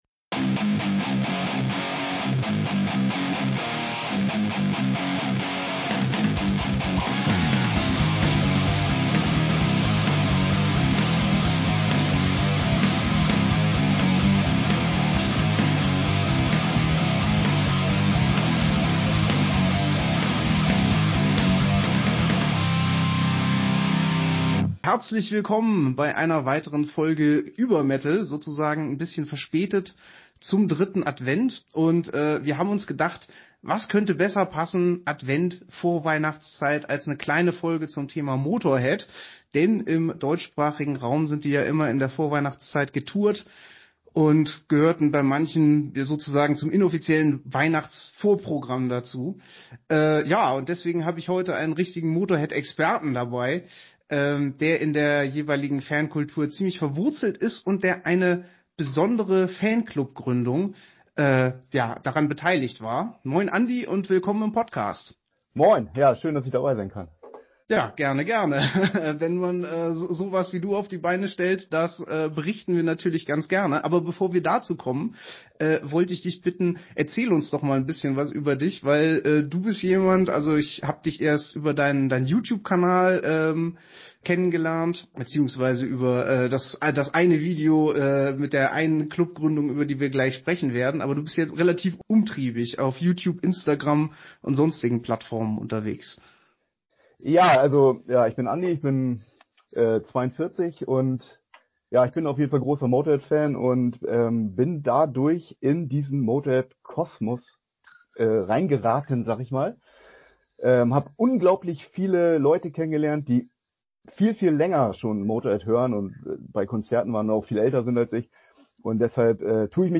Episode 72: We are Motörköppe | Interview